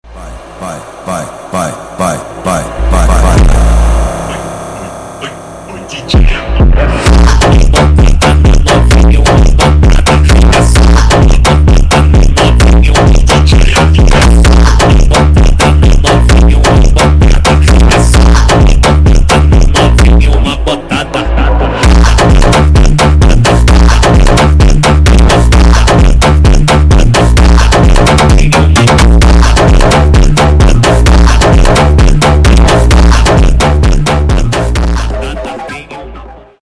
2023 » Громкие » Крутые » Фонк Скачать припев